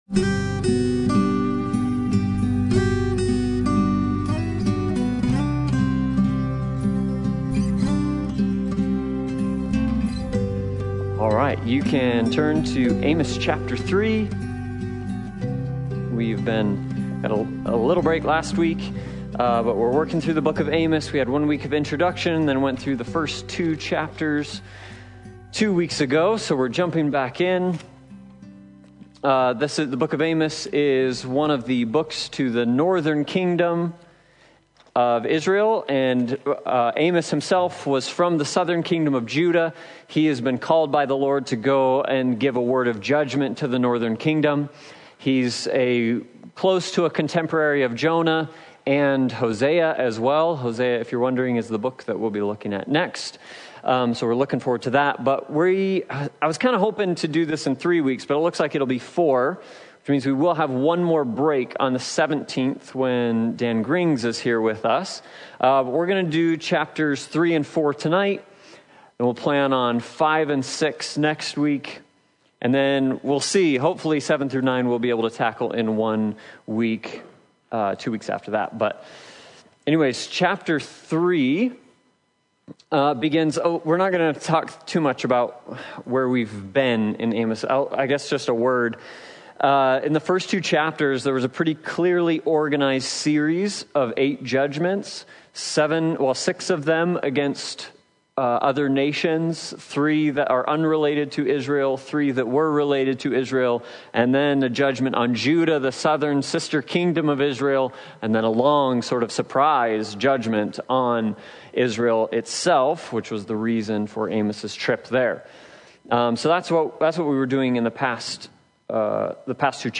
Sunday Bible Study « The Woman